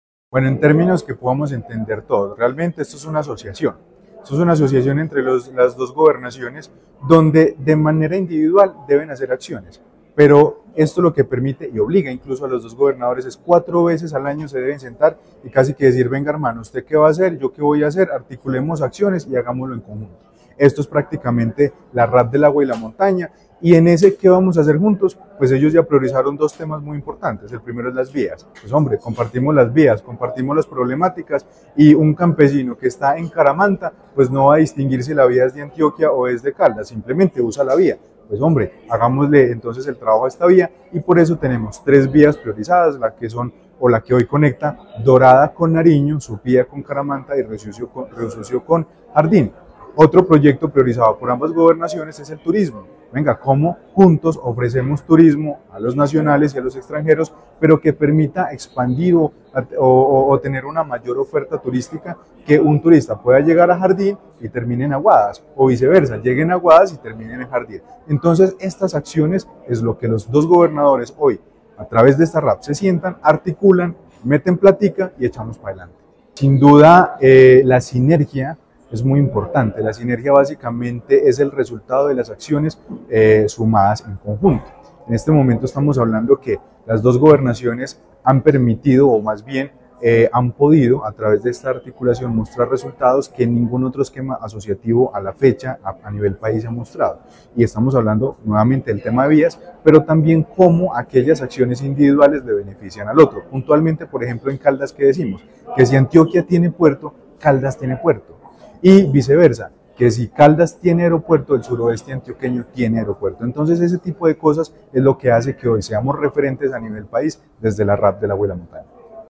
Ante la Asamblea de Antioquia, el secretario de Planeación de Caldas y gerente (e) de la RAP del Agua y la Montaña, Carlos Anderson García, y el director del Departamento Administrativo de Planeación de Antioquia (DAP), Eugenio Prieto Soto, dieron a conocer los avances en la implementación de la Región Administrativa de Planificación (RAP), iniciativa que trabaja por fortalecer la integración y el desarrollo conjunto entre estos dos departamentos hermanos.
Secretario de Planeación de Caldas y gerente (e) de la RAP del Agua y la Montaña, Carlos Anderson García.